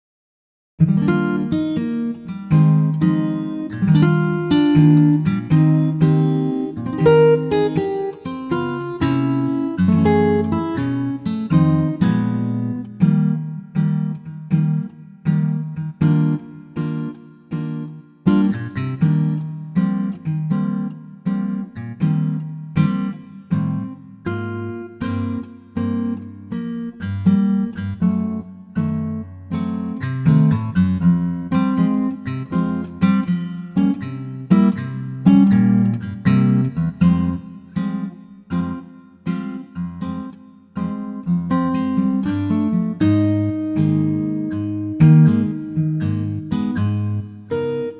key - Eb - vocal range - Bb to C
Gorgeous acoustic guitar arrangement
Same arrangement as M3038 except a full tone lower.